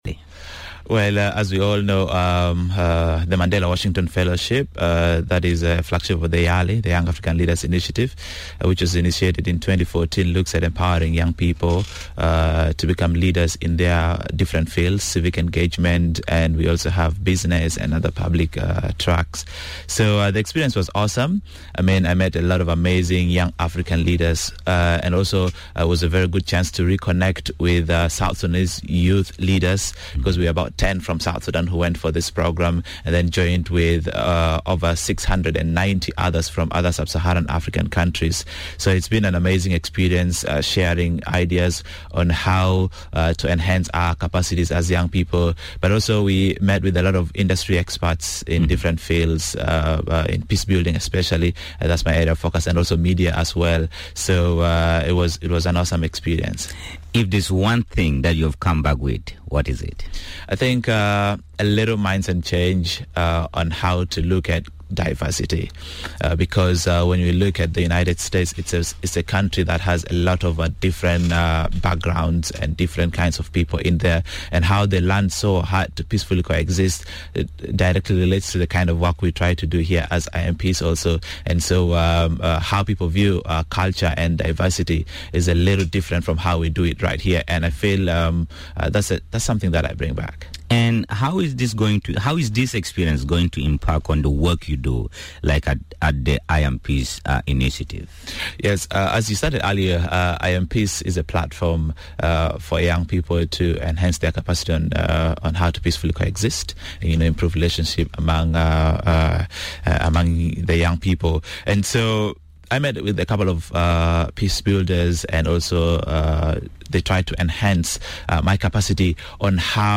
Radio Miraya / Interview